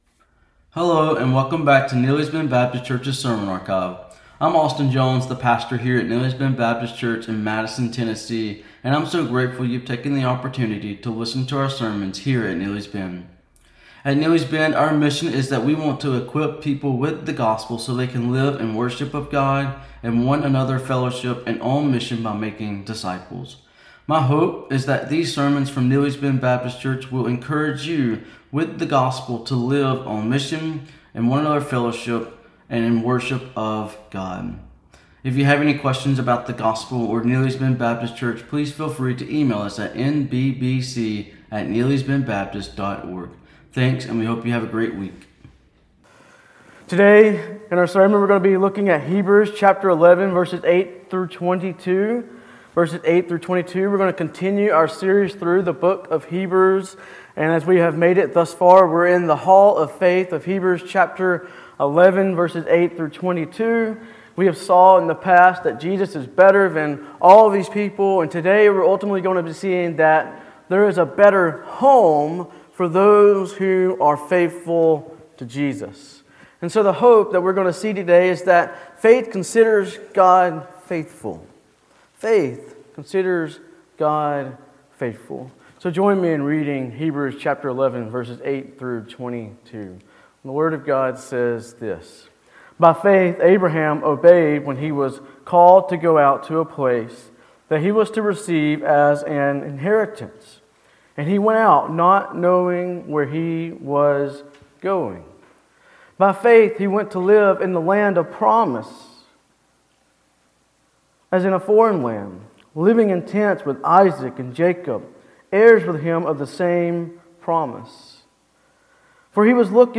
Sermons | Neely's Bend Baptist Church